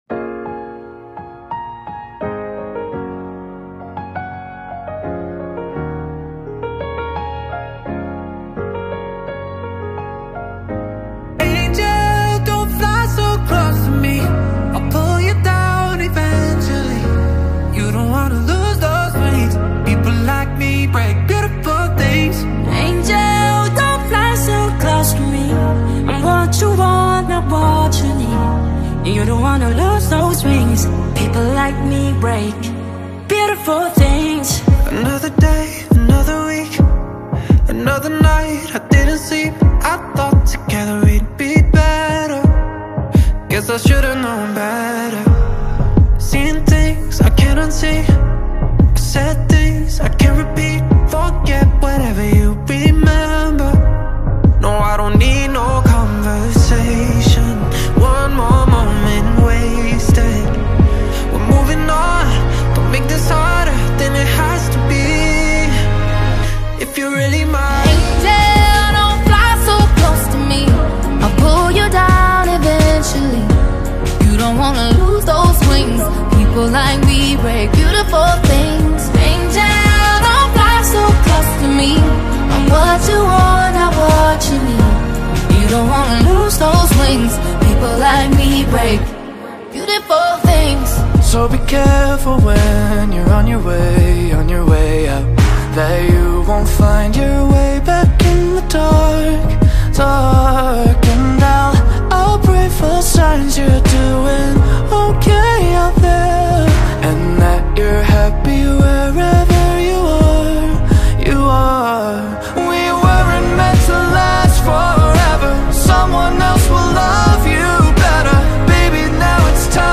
Все песни K-POP →